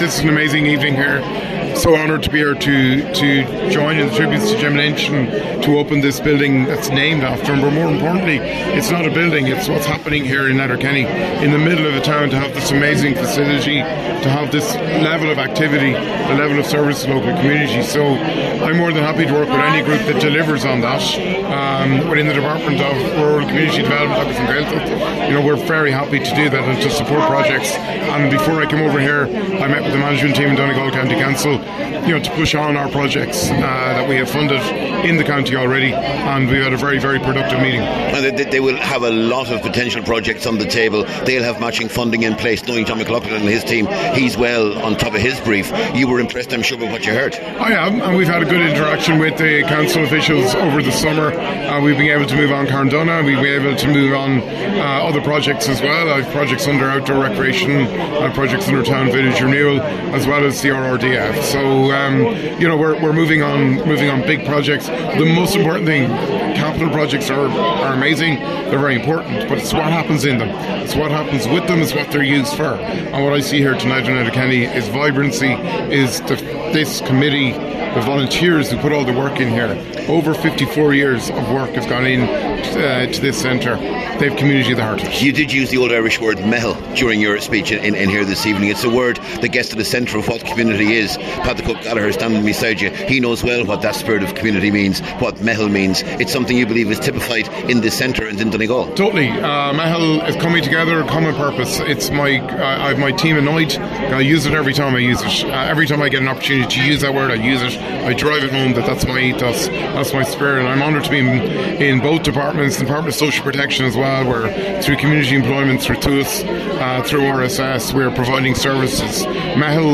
Speaking to Highland Radio News, he said there’s a great community spirit in Donegal, and he’ll do what he can to help that continue: